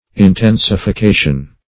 Intensification \In*ten`si*fi*ca"tion\, n.